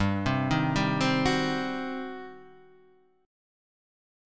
G Major 7th Suspended 4th Sharp 5th